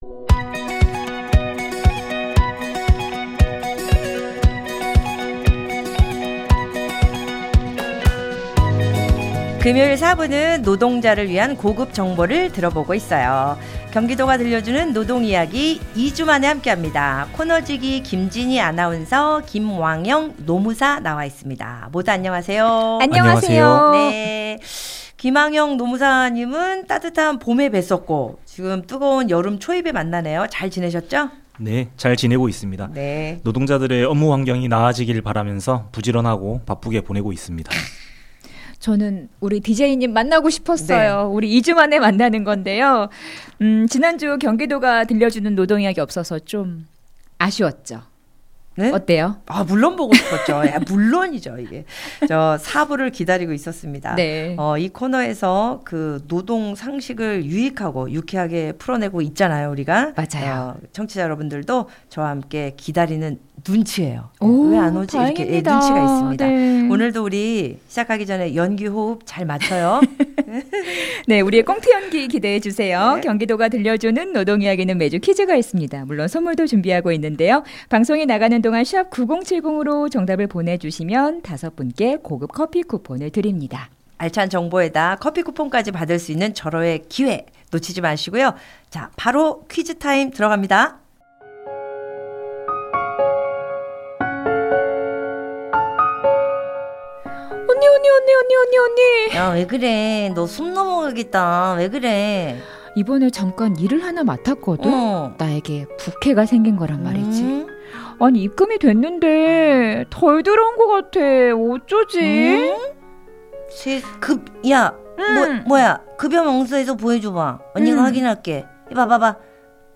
■방송: 경인방송 라디오